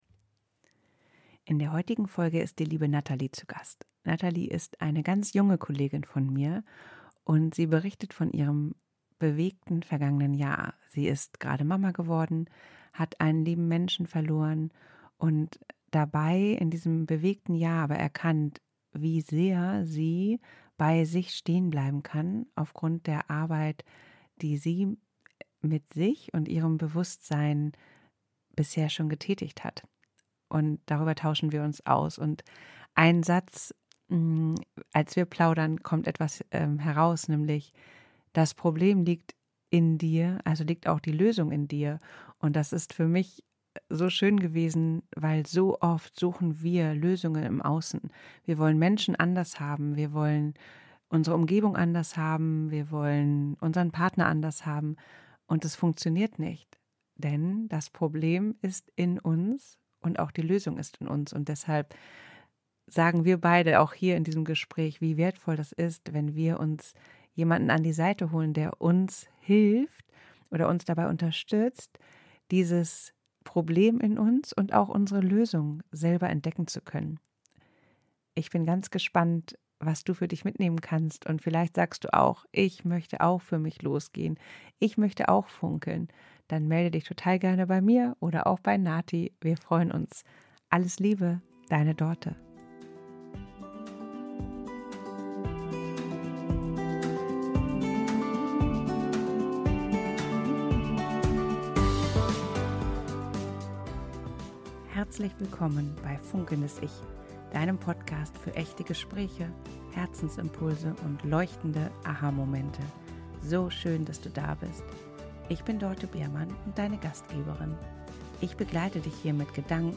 ein Sofa Gespräch